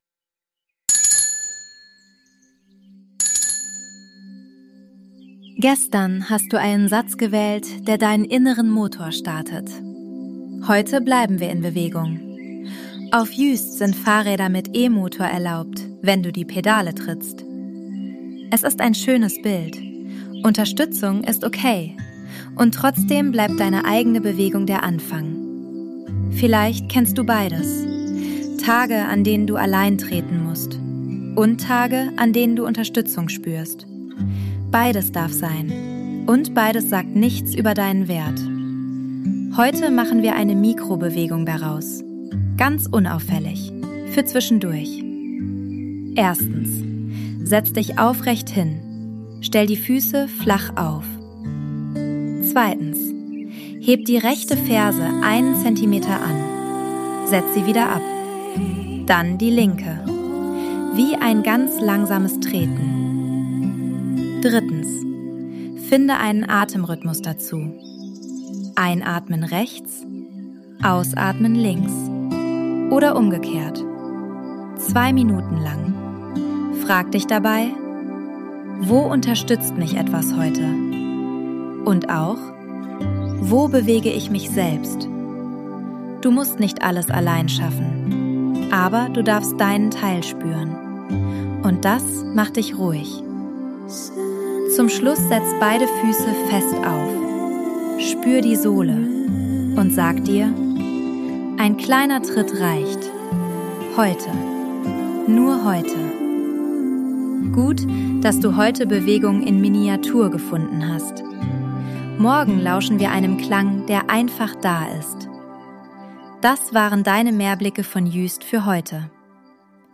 Sounds & Mix: ElevenLabs und eigene Atmos